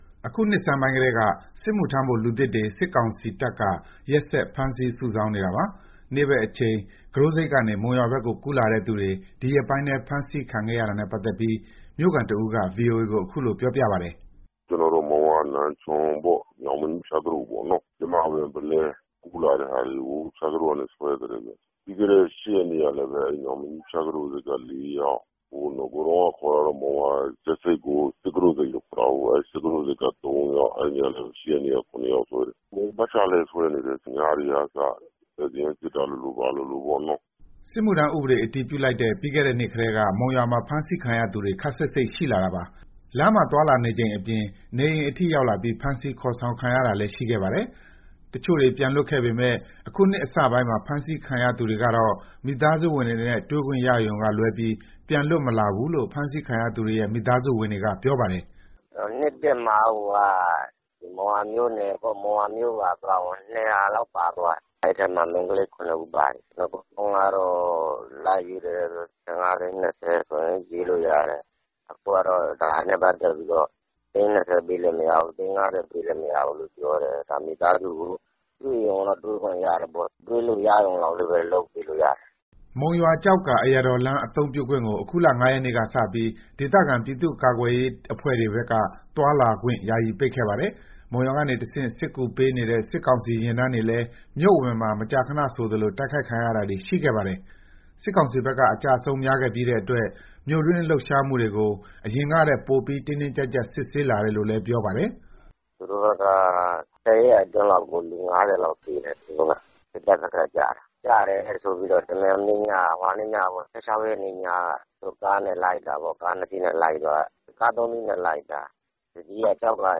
အခုနှစ်ဆန်းပိုင်းကတည်းက စစ်မှုထမ်းဖို့ လူသစ်တွေ စစ်ကောင်စီတပ်က ရက်ဆက် ဖမ်းဆီး စုဆောင်းနေတာပါ။ နေ့ဘက် အချိန် ကူးတို့ဆိပ်ကနေ မုံရွာဘက်ကို ကူးလာတဲ့သူတွေ ဒီရက်ပိုင်းထဲ ဖမ်းဆီးခံခဲ့ရတာနဲ့ ပတ်သက်ပြီး မြို့ခံတဦးက VOA ကို အခုလို ပြောပါတယ်။